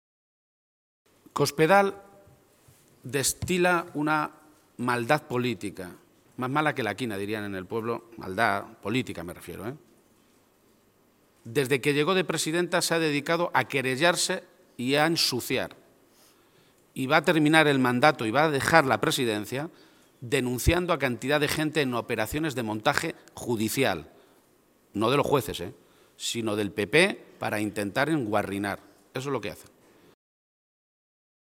García-Page se pronunciaba de esta manera esta mañana, en Toledo, a preguntas de los medios de comunicación en la que se le preguntaban por varios asuntos de actualidad.
Cortes de audio de la rueda de prensa